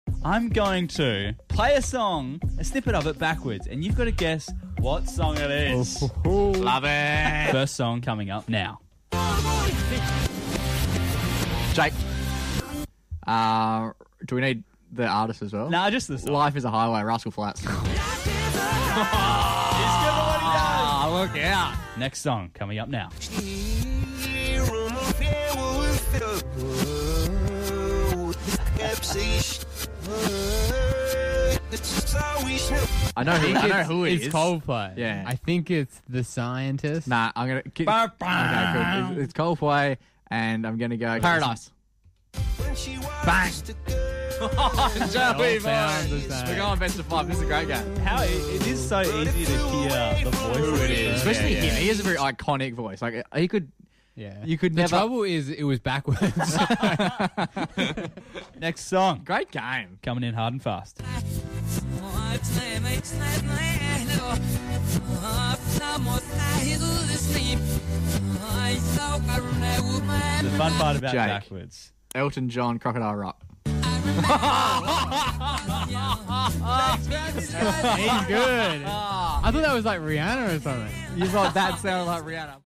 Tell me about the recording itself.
Here’s a game we played sound effects free download By the.macpack.podcast 0 Downloads 7 months ago 82 seconds the.macpack.podcast Sound Effects About Here’s a game we played Mp3 Sound Effect Here’s a game we played one of our recent community radio shows!